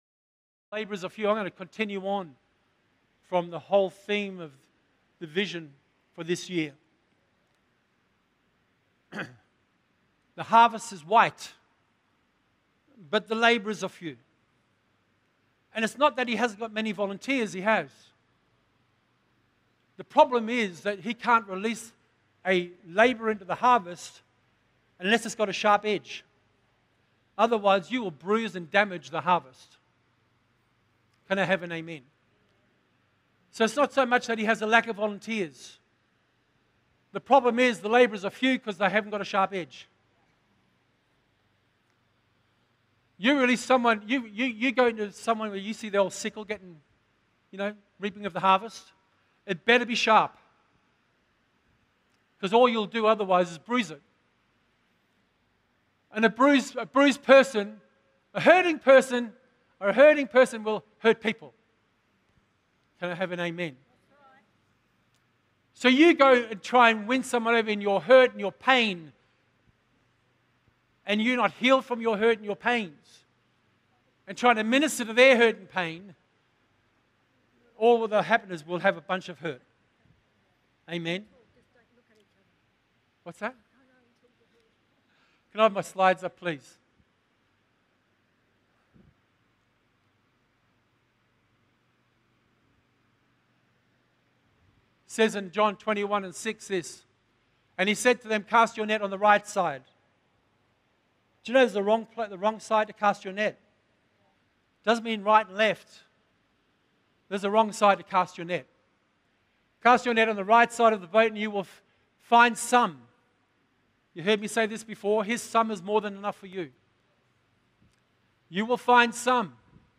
All sermons can now be viewed live or later via the Westpoint City Church YouTube Channel !